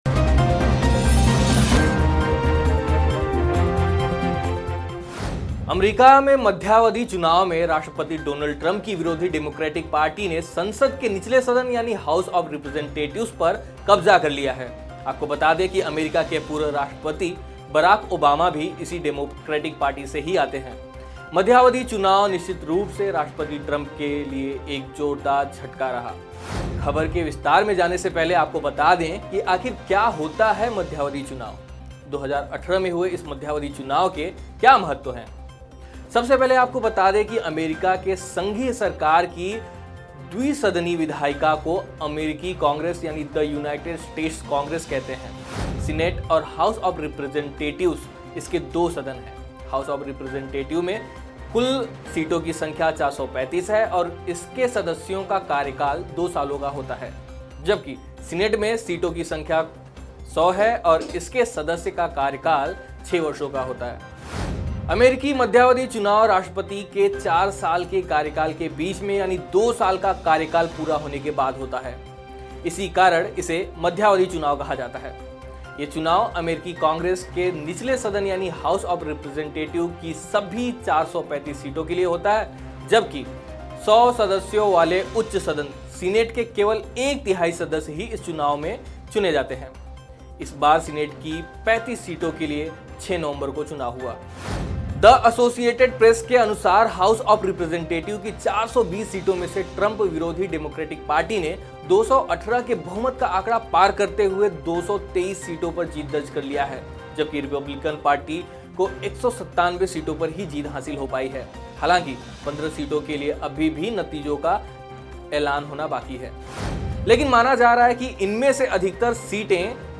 न्यूज़ रिपोर्ट - News Report Hindi / US मध्यावधि चुनाव: डेमोक्रैट्स को मिला बहुमत, डोनल्ड ट्रम्प की बढ़ी मुश्किलें